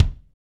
Index of /90_sSampleCDs/Northstar - Drumscapes Roland/DRM_Funk/KIK_Funk Kicks x
KIK FNK K06L.wav